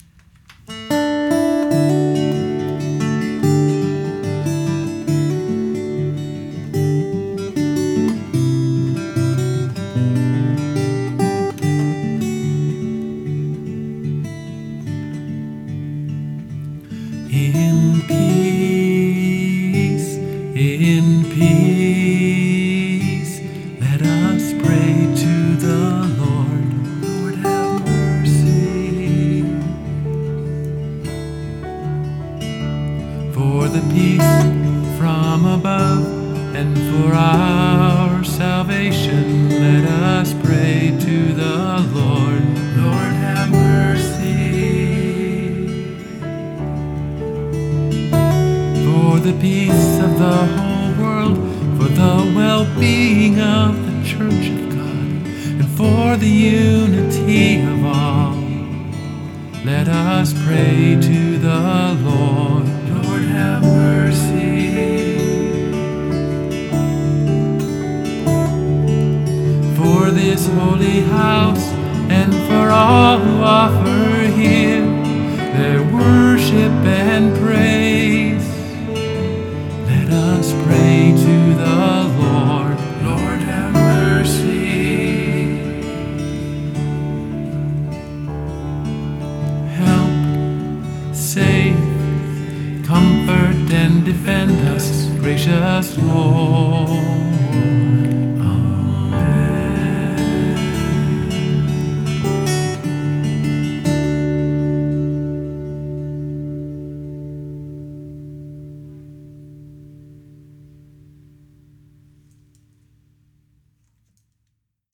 MP3 Demo
Comments: Going through a recent book that explains the Lutheran Liturgy, I was looking at the familiar Kyrie, and just started picking something simple and repetitive on guitar, likewise tried a really simple melody to sing.